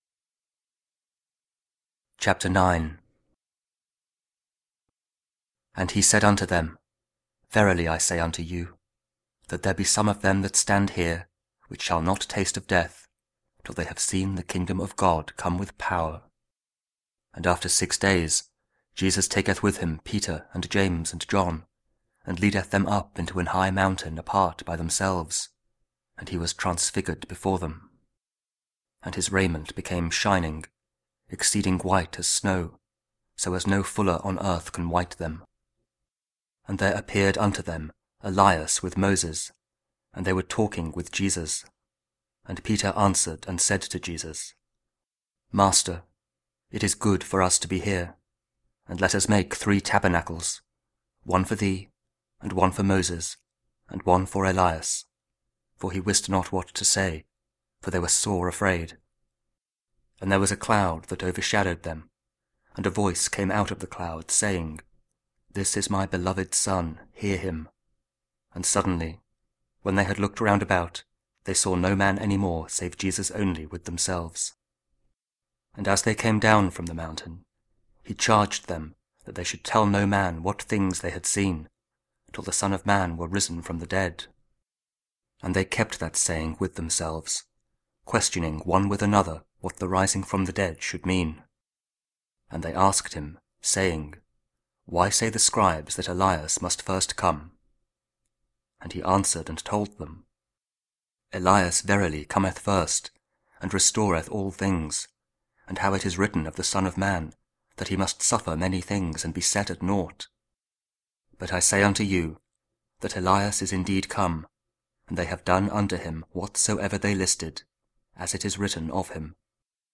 Mark 9: 30-37 – 25th Sunday Year B and Tuesday 7 (Audio Bible, Spoken Word)